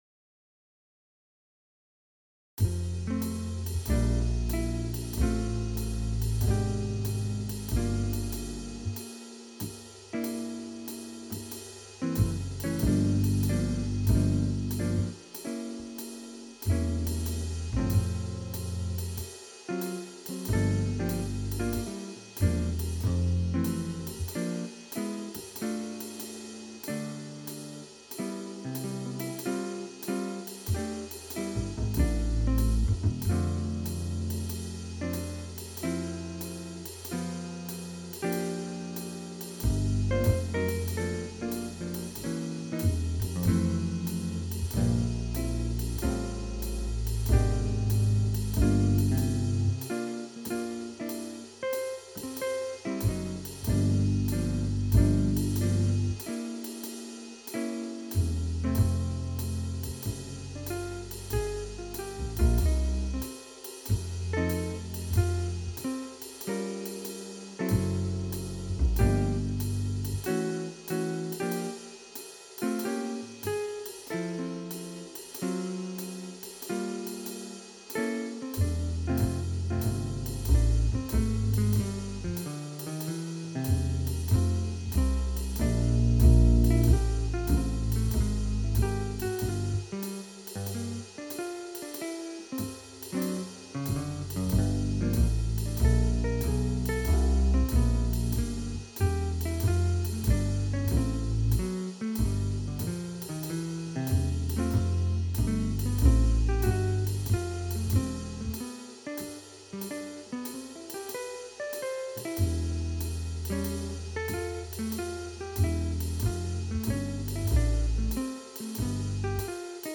Ich habe mal 3 Klangbeispiele erzeugt.
modo-bass-2-jazz.mp3